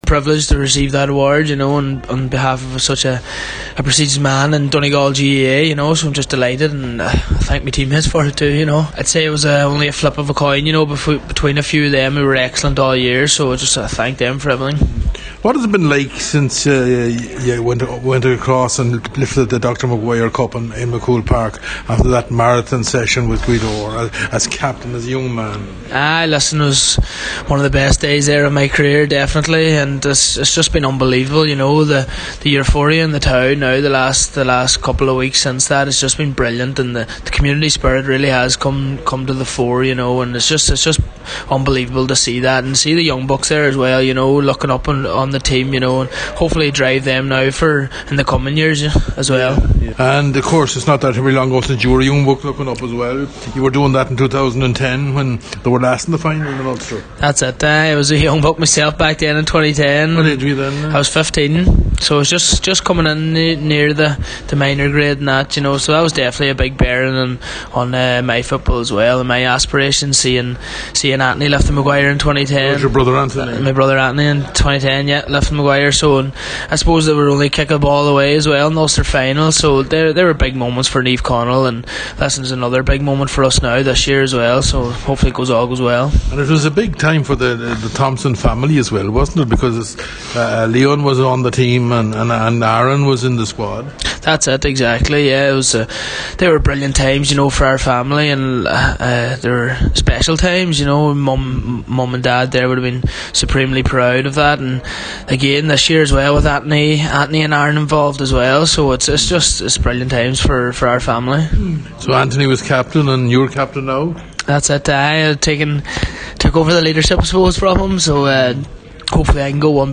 this weeks Ulster Final launch in Omagh